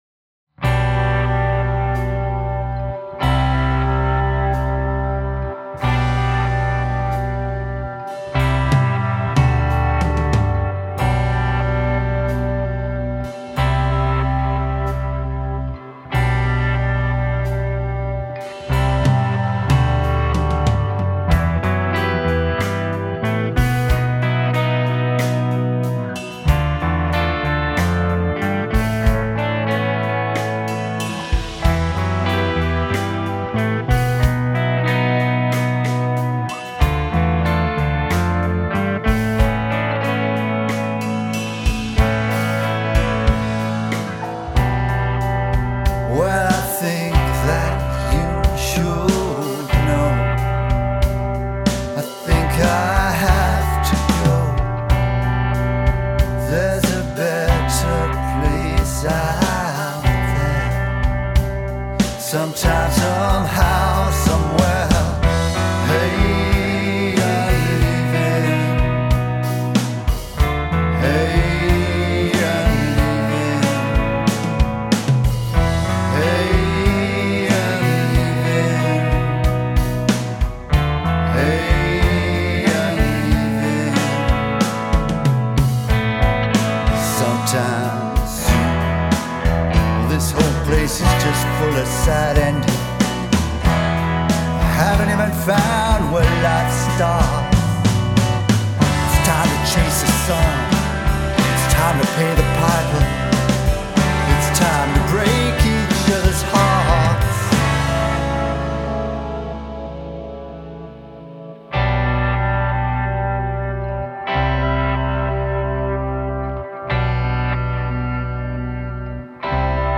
Shorter, less complicated, poppier...
Midrange focus maybe needed some tweaking, and I wanted to hear some more of the snare's nice depth.
Love the sound of the guitars during the interludes!